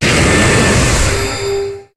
Cri d'Ultra-Necrozma dans Pokémon HOME.